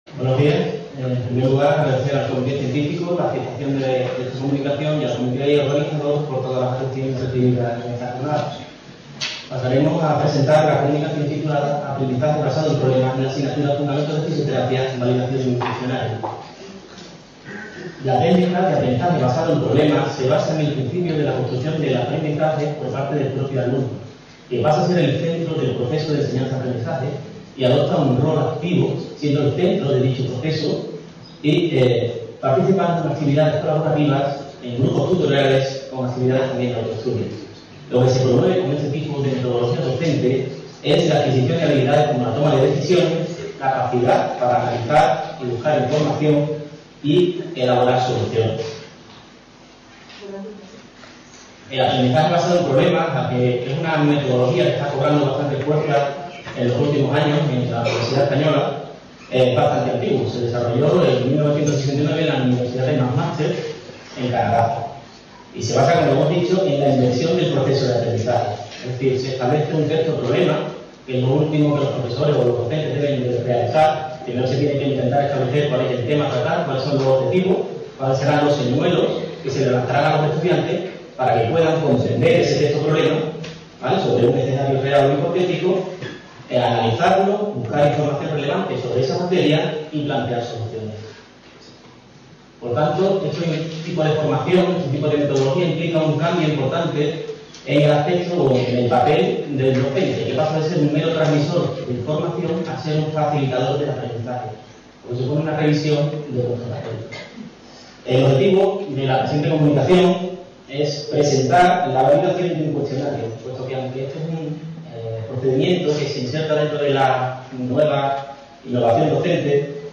VIII Jornadas Nacionales y IV Internacionales de Educación en Fisioterapia y IX Jornadas Interuniversitarias del Área de Conocimiento Fisioterapia
Reunion, debate, coloquio...